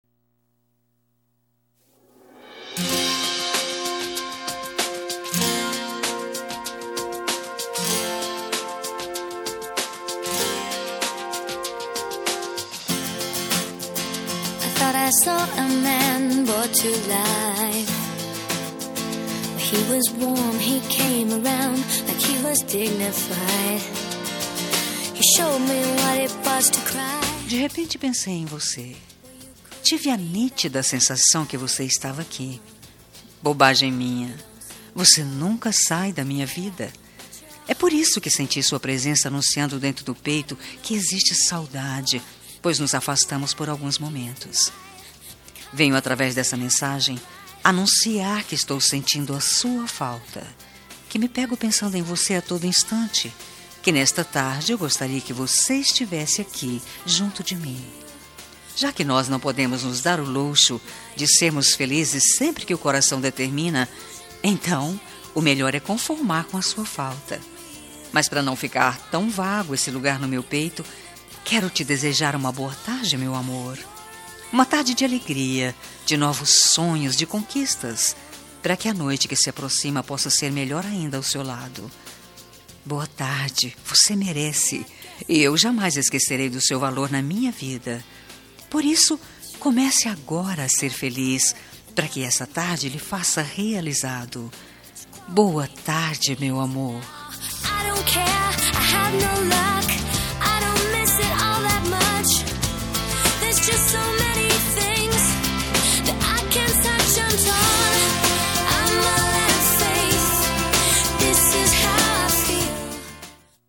Telemensagem de Boa Tarde – Voz Feminina – Cód: 6312 – Romântica